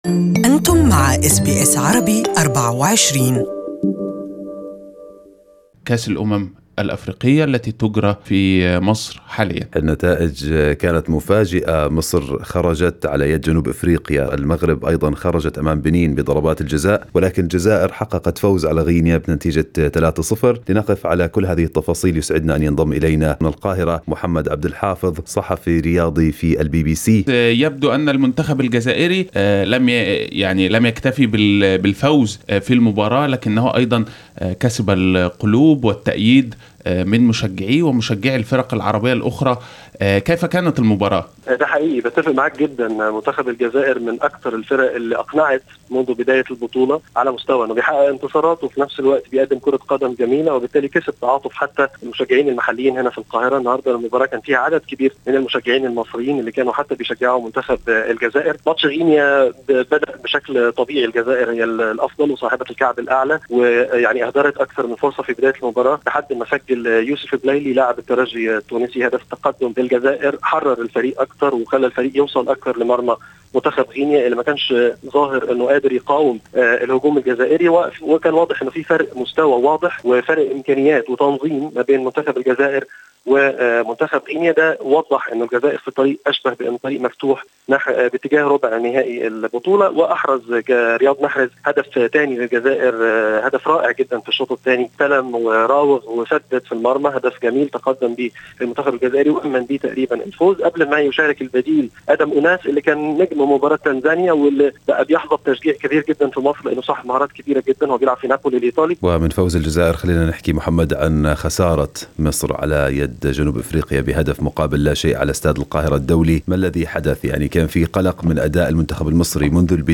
Analysis with sports journalist